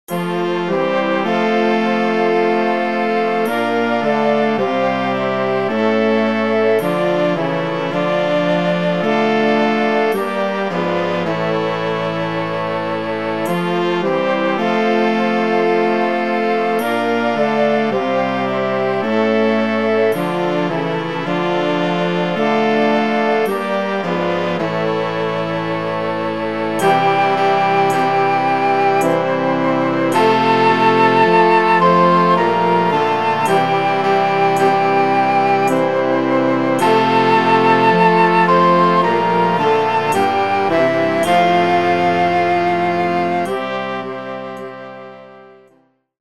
pasyjna
wielkopostna